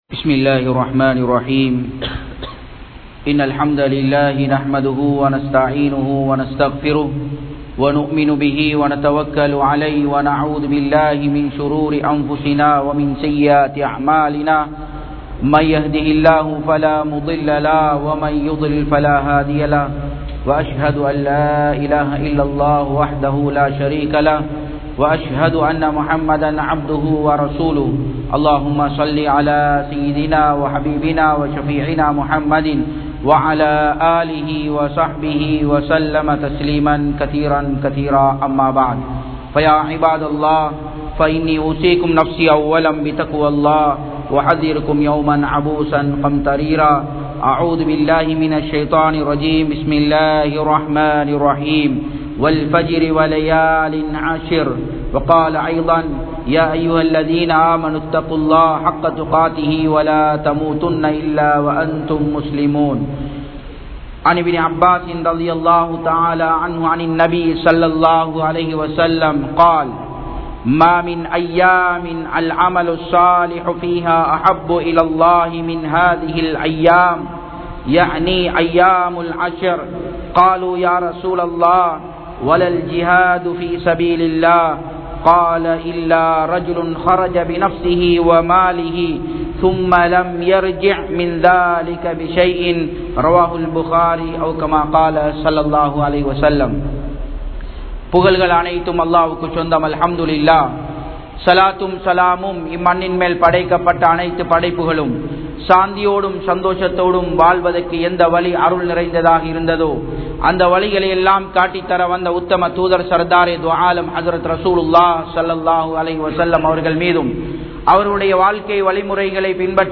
Amal Seiya Sirantha 10 Naatkal (அமல் செய்ய சிறந்த 10 நாட்கள்) | Audio Bayans | All Ceylon Muslim Youth Community | Addalaichenai
Japan, Nagoya Port Jumua Masjidh 2017-08-25 Tamil Download